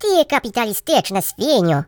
share/hedgewars/Data/Sounds/voices/Russian_pl/Youllregretthat.ogg
Youllregretthat.ogg